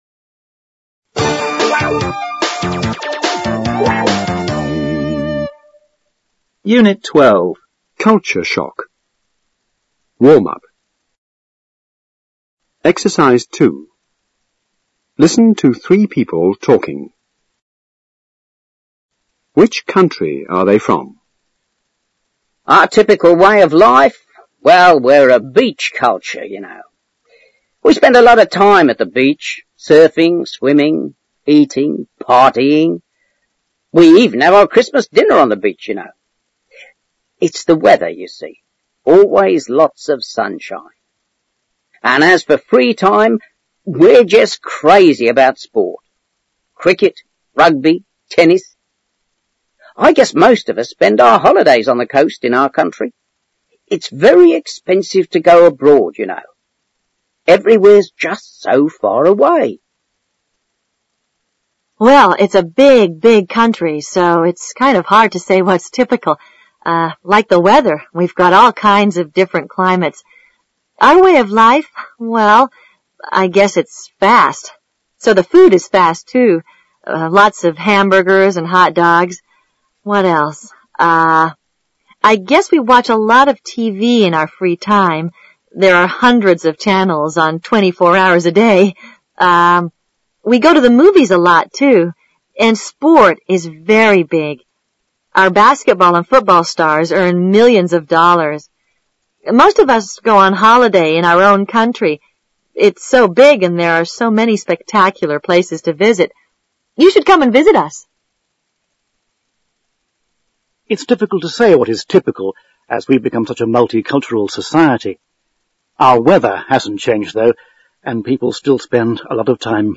Listen to three people talking.